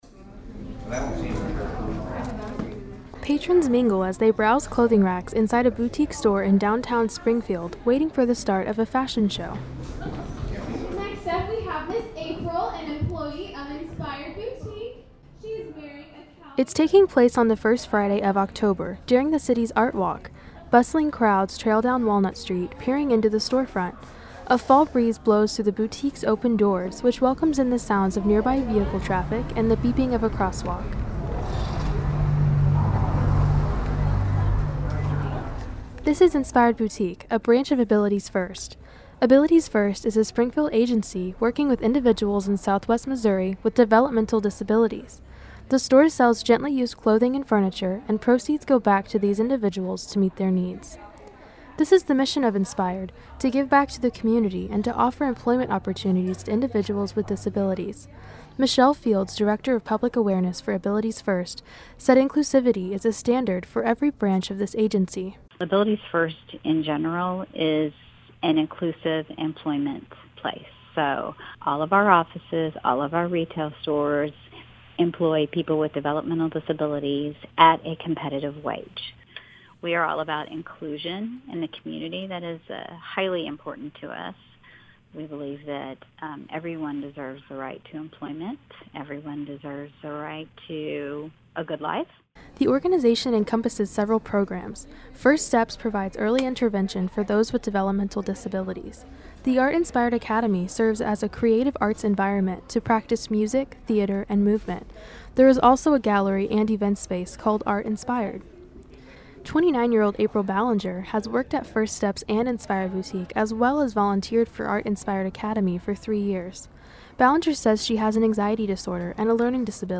The bustling crowd has gathered on the first Friday of October, during the city’s Art Walk. A fall breeze blows through the boutique’s open doors along Walnut Street, which welcomes in the sounds of nearby vehicle traffic and the beeping of a crosswalk.